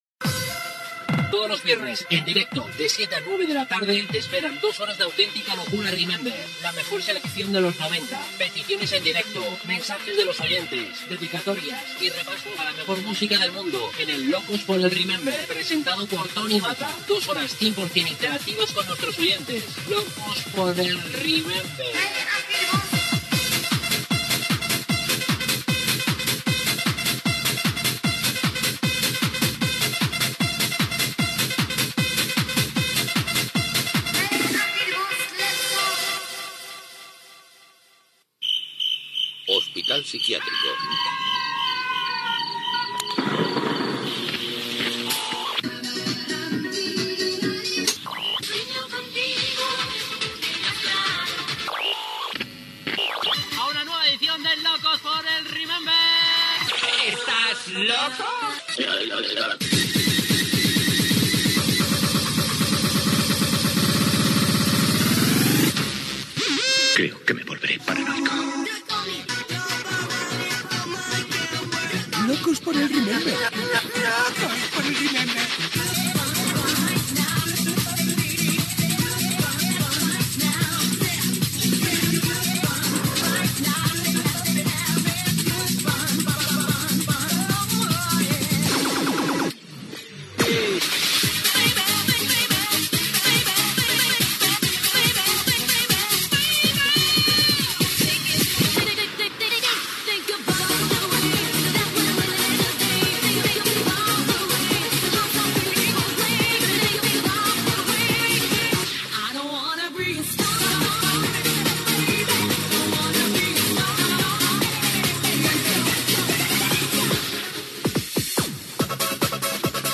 Careta del programa, mescla musical, presentació, data, emissores que emeten el programa,webs d'Internet que distribueixen el programa, indicatiu i tema musical
Musical
FM